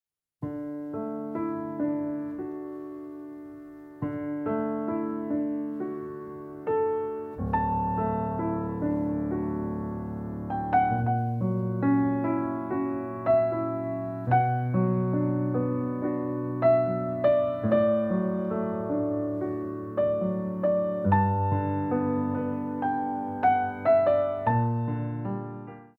Révérence